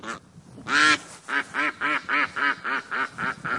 quack.mp3